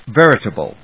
音節ver・i・ta・ble 発音記号・読み方
/vérəṭəbl(米国英語), ˈverɪtʌbʌl(英国英語)/